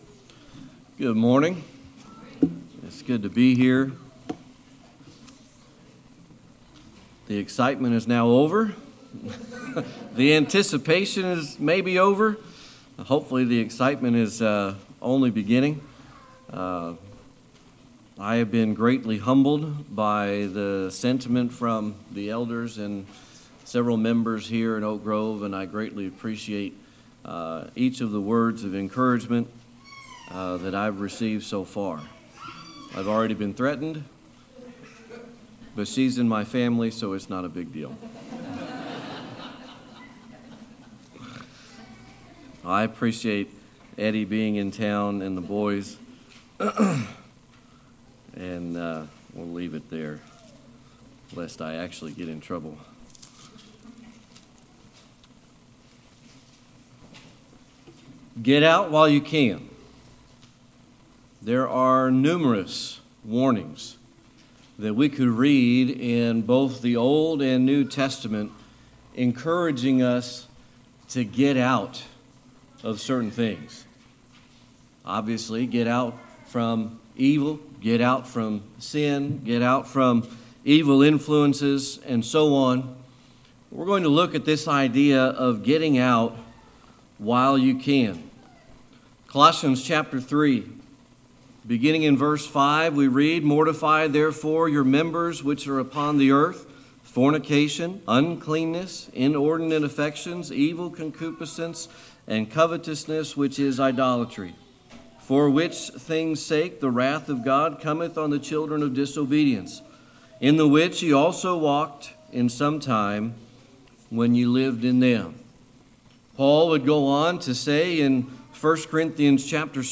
James 4:14-17 Service Type: Sunday Morning Worship Get out while you can.